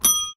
bicyclebell.ogg